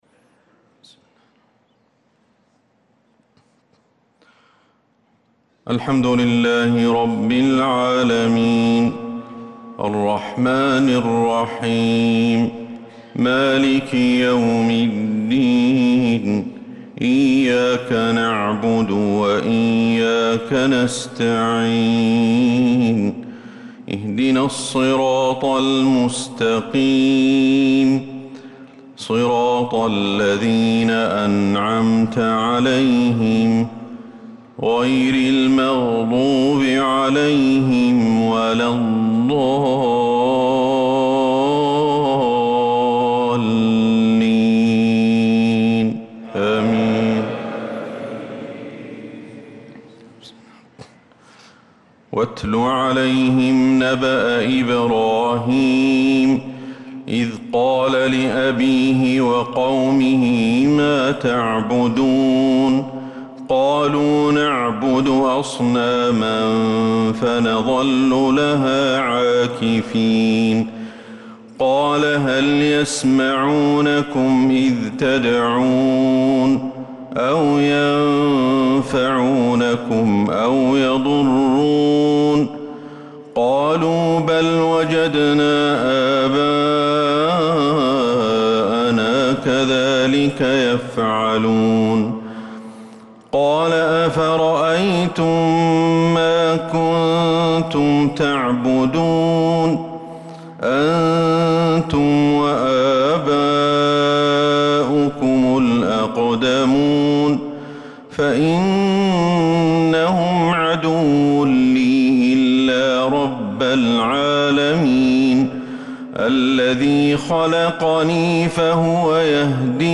صلاة الفجر للقارئ أحمد الحذيفي 26 ذو القعدة 1445 هـ
تِلَاوَات الْحَرَمَيْن .